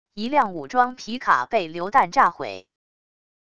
一辆武装皮卡被榴弹炸毁wav音频